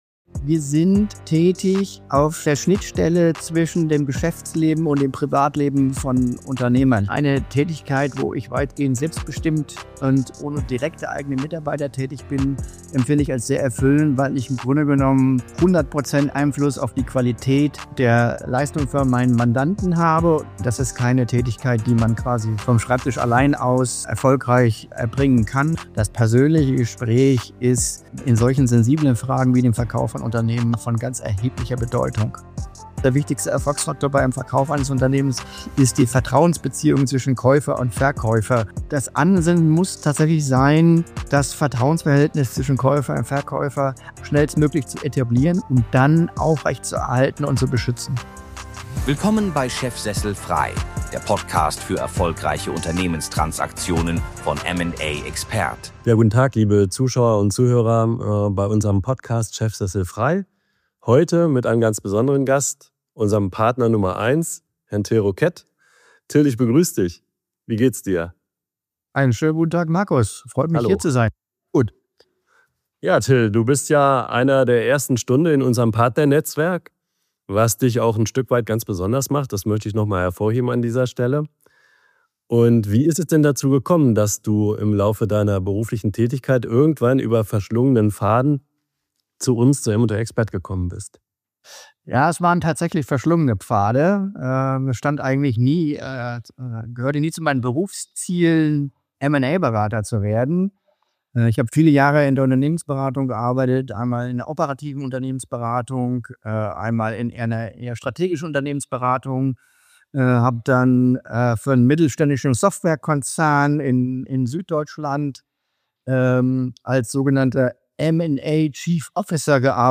Ein Gespräch über Lebensentscheidungen, Stolz, Nachfolge, Werte – und eine Branche, die sich selbst neu erfindet.